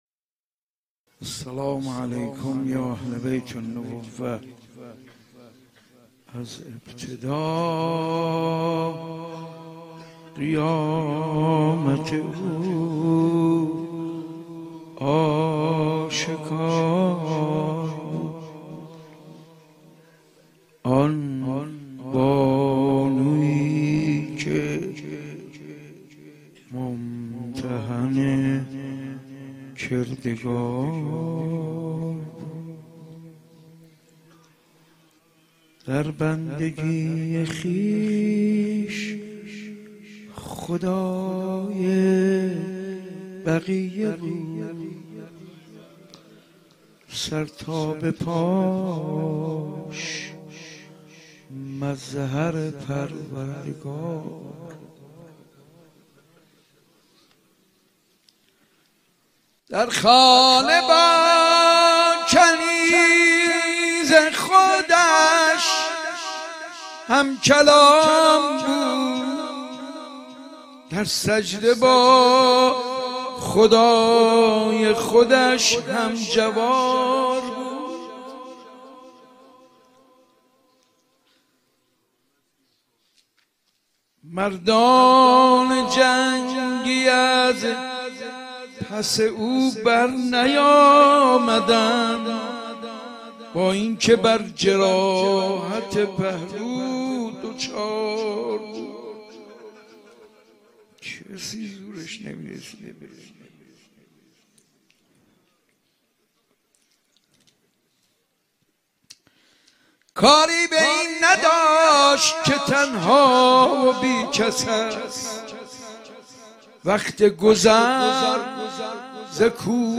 مرثیه فاطمیه
حسینیه بیت الزهرا سلام الله علیها